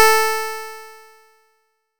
nes_harp_A4.wav